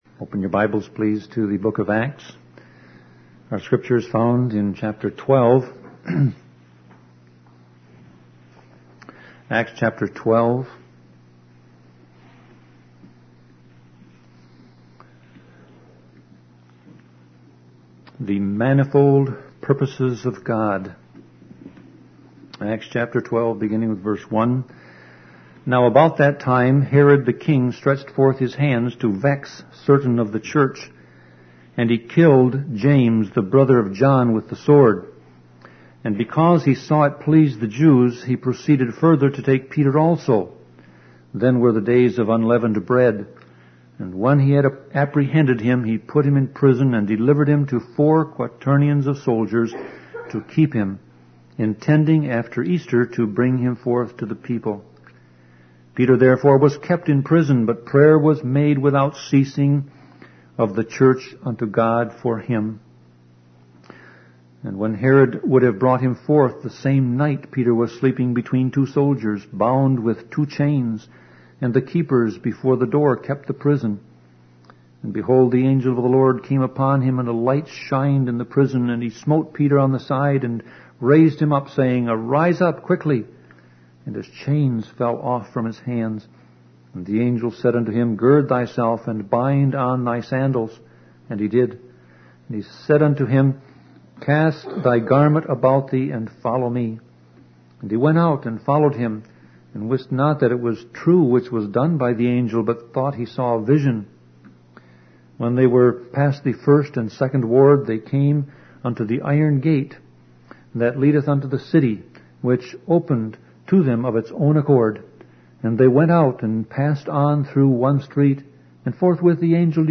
Sermon Audio Passage: Acts 12